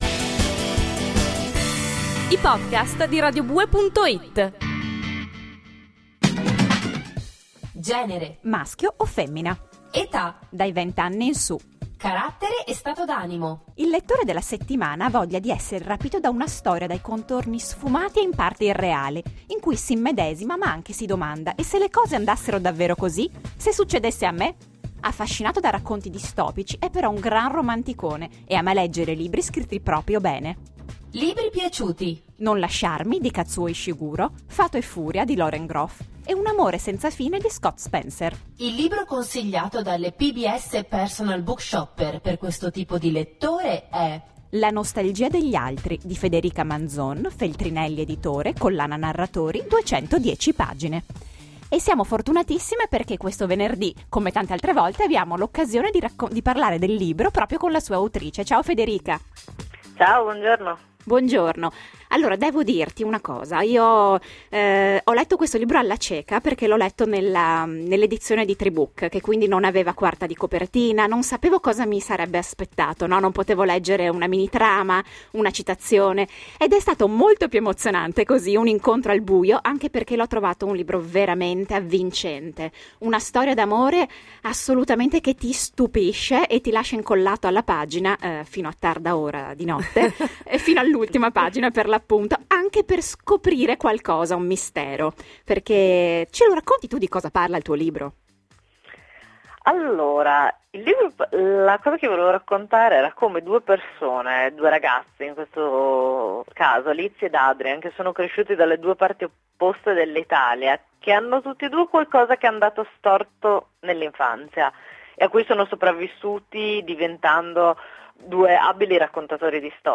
Ascolta la  risposta dell’autrice nella prima parte dell’intervista (o scaricala qui):